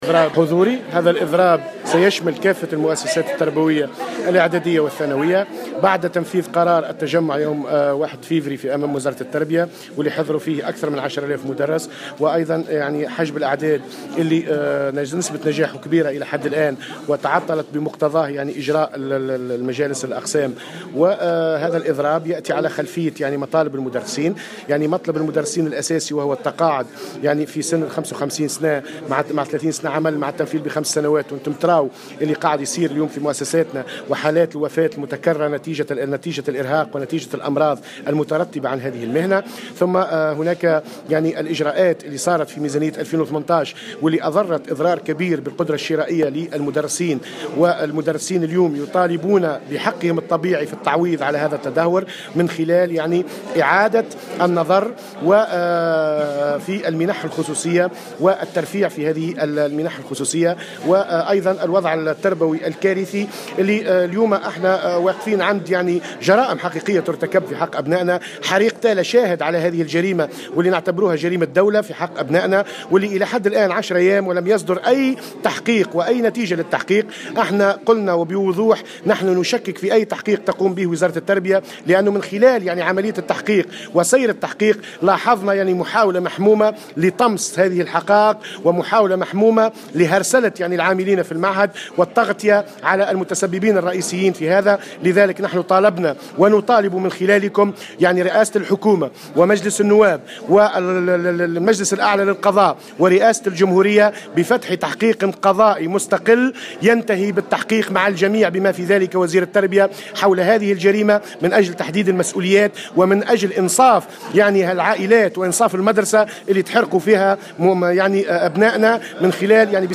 خلال ندوة صحفية اليوم